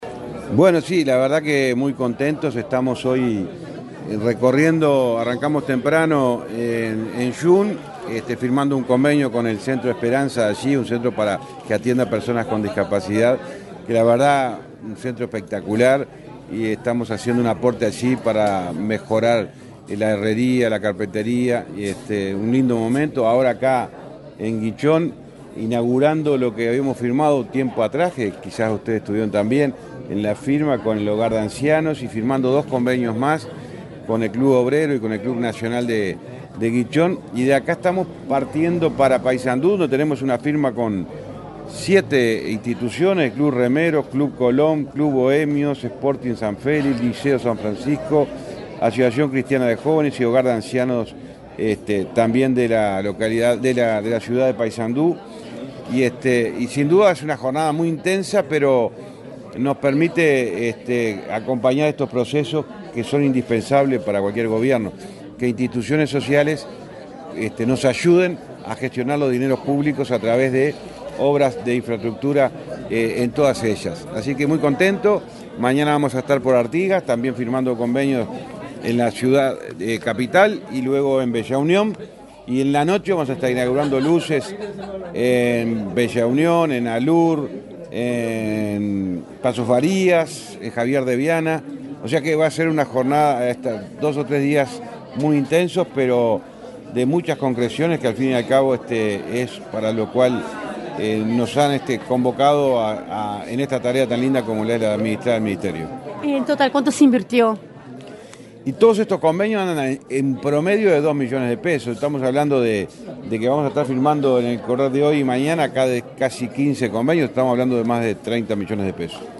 Declaraciones del ministro de Transporte, José Luis Falero
El ministro de Transporte, José Luis Falero, dialogó con la prensa en Paysandú, durante una recorrida, en la que firmó convenios sociales con varias